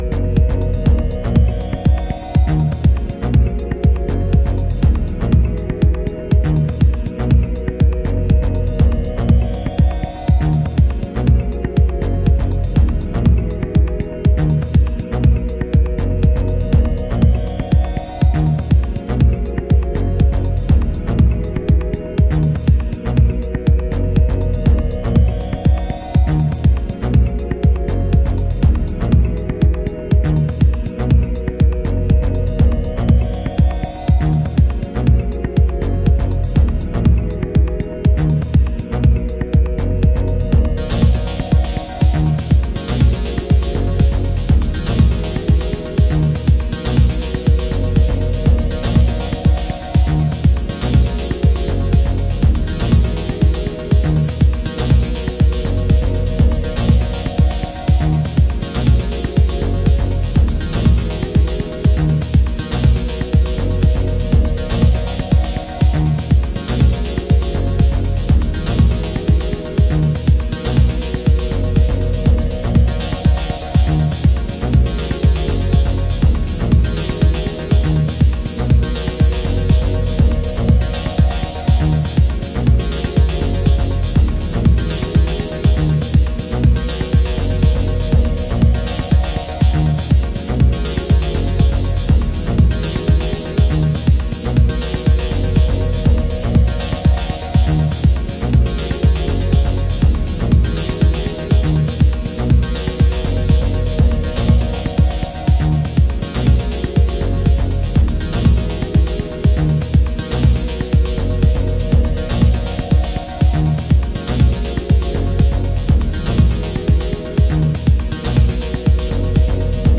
Detroit House / Techno
アトモフェリック・ディープ〜ダブテクノしています